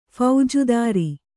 ♪ phaujudāri